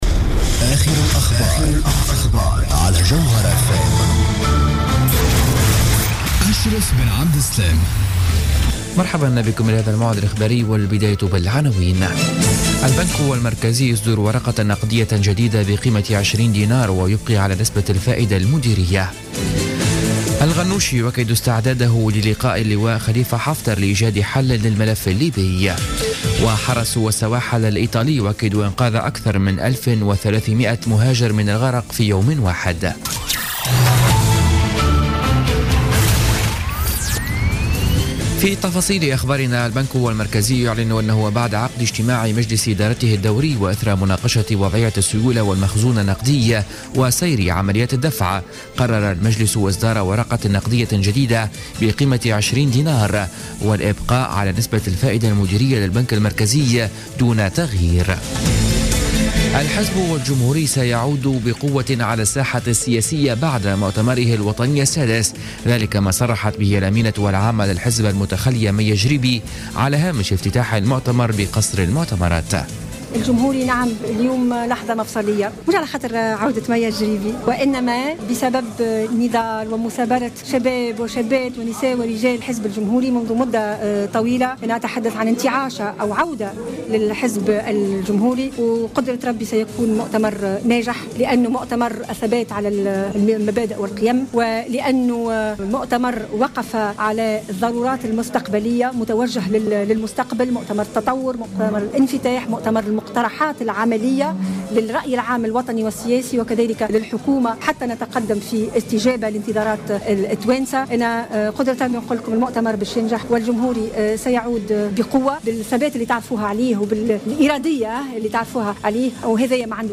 Journal Info 00h00 du samedi 4 Février 2017